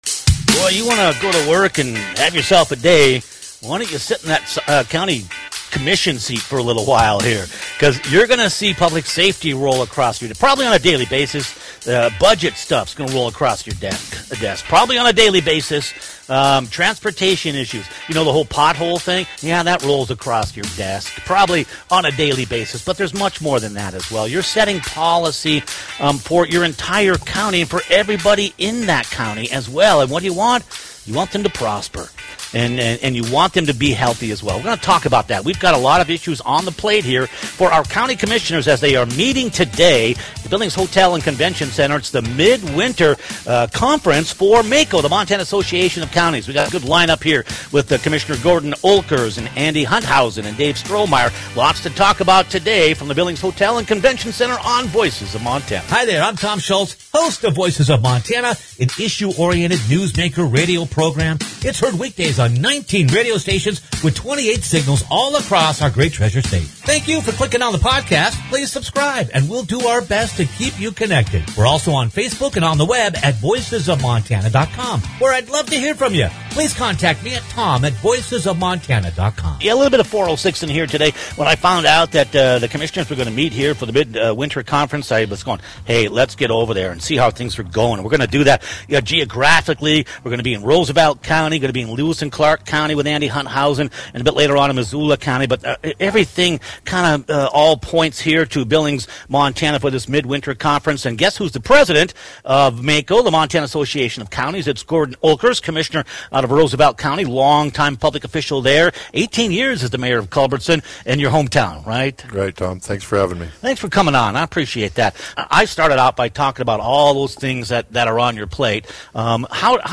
County by County the Work’s Getting Done – LIVE from MACo - Voices of Montana
Featuring MACo President and Roosevelt County Commissioner Gordon Oelkers, Lewis & Clark County Commissioner Andy Hunthausen, and Missoula County Commissioner Dave Strohmaier